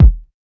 Phat Kick Sound E Key 99.wav
Royality free bass drum tuned to the E note. Loudest frequency: 101Hz
.WAV .MP3 .OGG 0:00 / 0:01 Type Wav Duration 0:01 Size 75,21 KB Samplerate 44100 Hz Bitdepth 32 Channels Mono Royality free bass drum tuned to the E note.
phat-kick-sound-e-key-99-PUJ.ogg